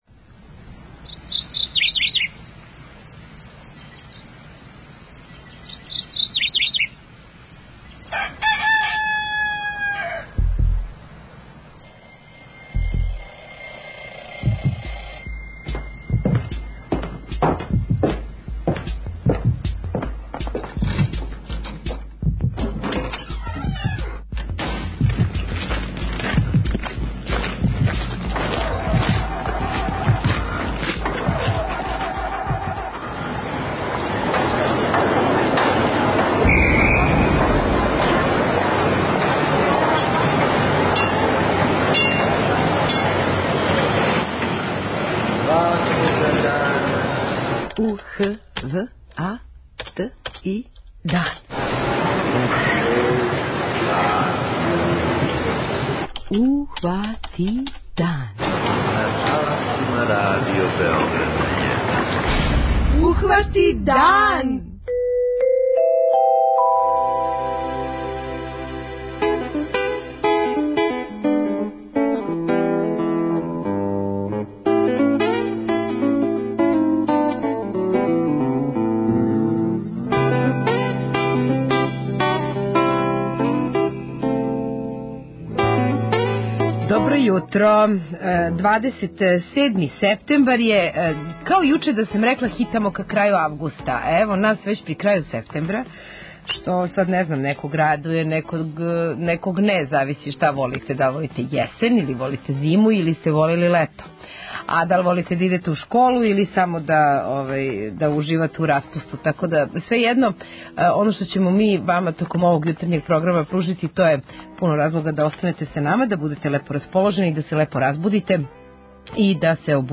преузми : 21.57 MB Ухвати дан Autor: Група аутора Јутарњи програм Радио Београда 1!